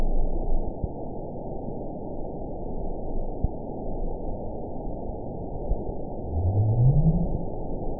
event 921472 date 10/16/24 time 01:22:12 GMT (6 months, 2 weeks ago) score 9.65 location TSS-AB07 detected by nrw target species NRW annotations +NRW Spectrogram: Frequency (kHz) vs. Time (s) audio not available .wav